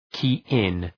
key-in.mp3